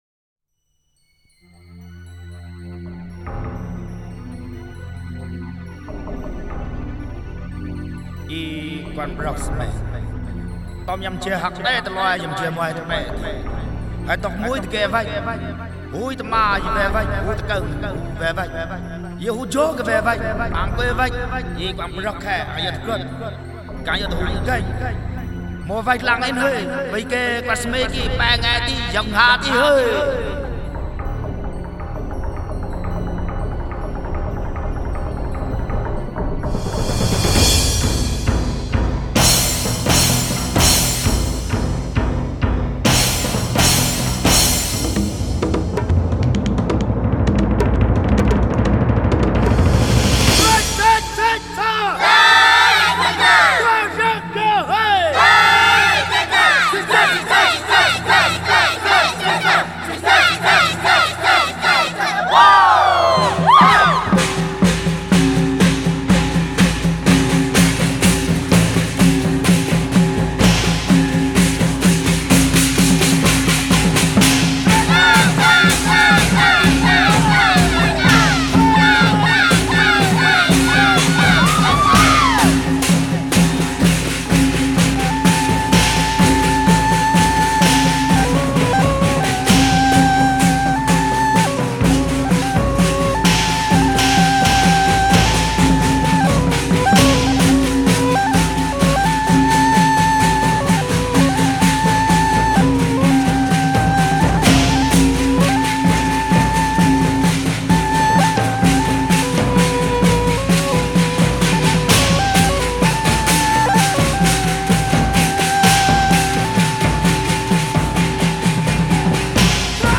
佤族